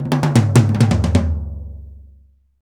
Index of /90_sSampleCDs/Roland L-CDX-01/TOM_Rolls & FX/TOM_Tom Rolls
TOM TOM R06L.wav